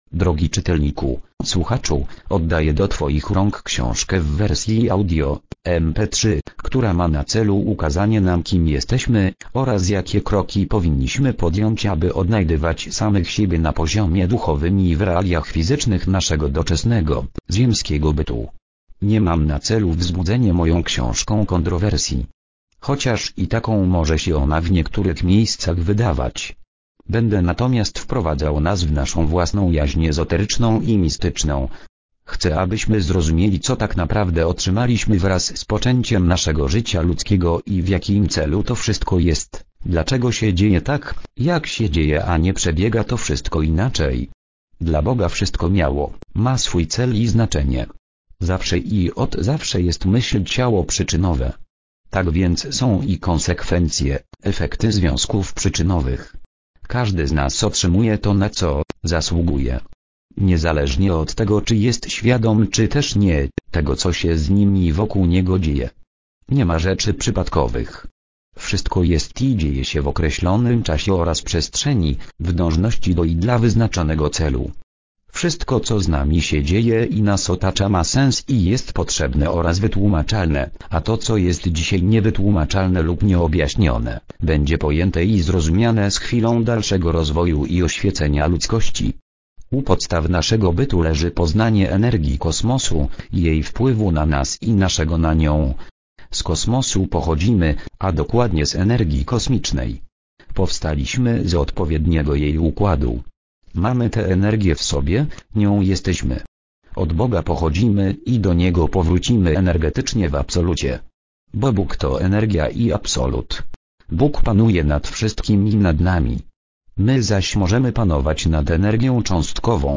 Esencja jogi - Guru Dharam Singh Khalsa, Darryl O'Keeffe Przyk�adowe fragmenty Przyk�adowy fragment Opis Ksi��ka w wersji audio.